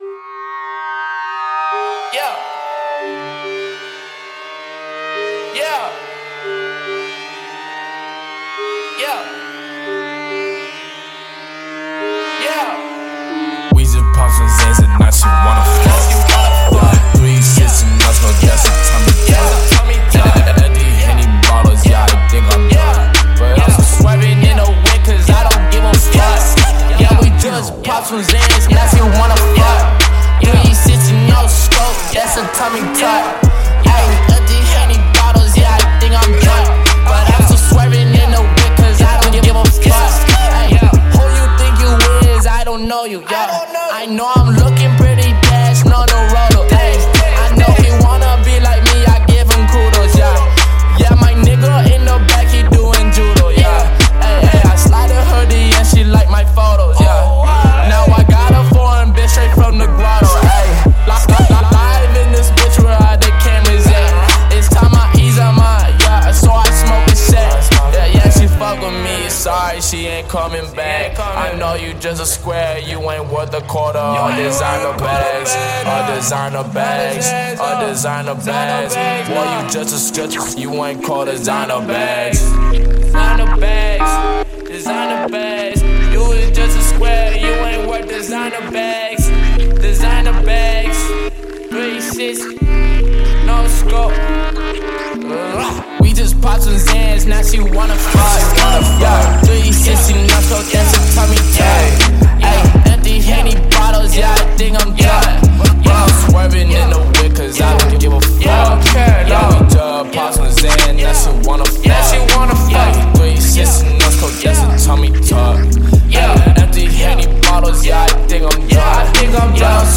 带有键和节奏标记的循环，包括一击和人声。
•010 X 808S